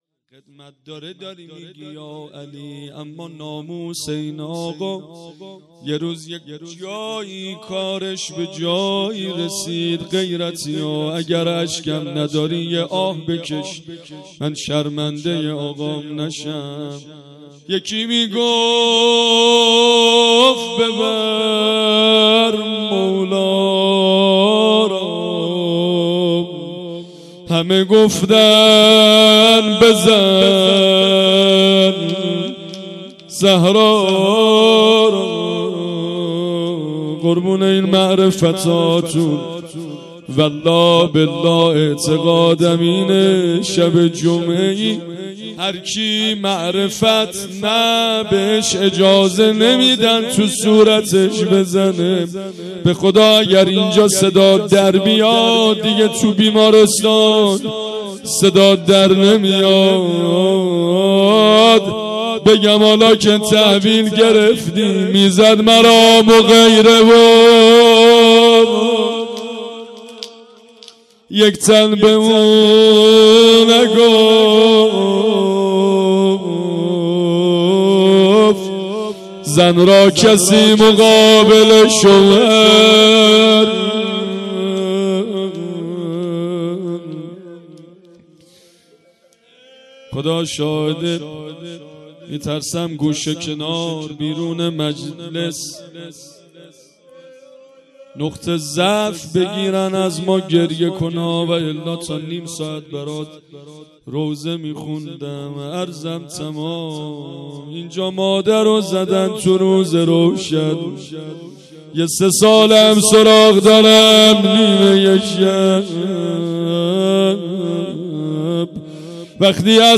هیئت عقیله بنی هاشم سبزوار
مراسم عید غدیر ۱۴۰۴